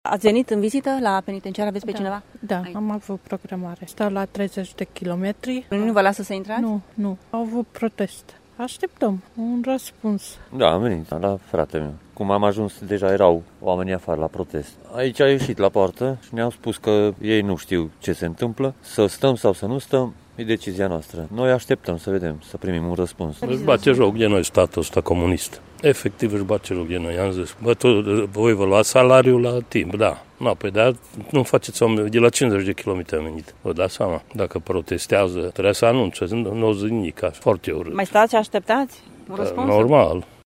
Protestul din această dimineață a blocat și programul vizitelor la Penitenciar. Mai multe persoane au venit de la zeci de kilometri distanță pentru vizitele programate la deținuții din Târgu Mureș: